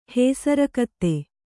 ♪ hēsara katte